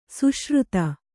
♪ suśruta